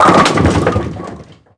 bowling01.mp3